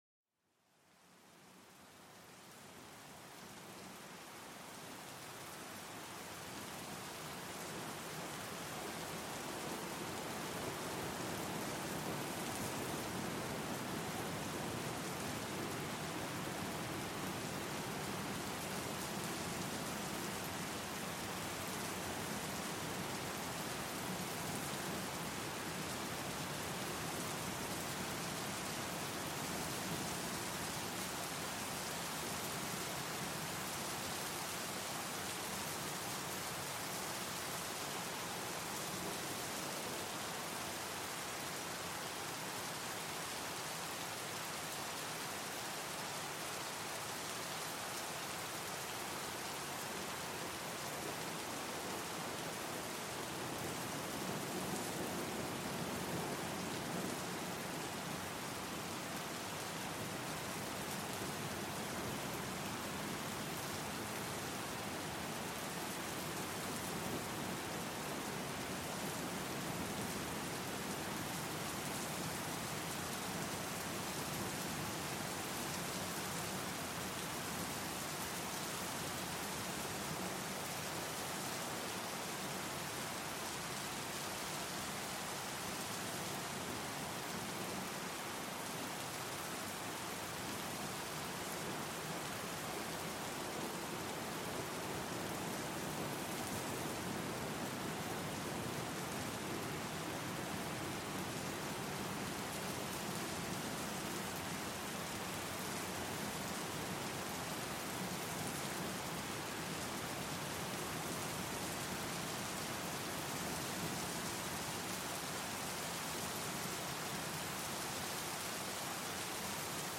Pluie Battante: Un Doux Répit pour l'Esprit
Dans cet épisode, nous plongeons au cœur d'une averse torrentielle, capturant l'essence même de la pluie battante. Laissez-vous envelopper par le rythme apaisant des gouttes frappant le sol, une symphonie naturelle qui calme l'esprit et revitalise l'âme.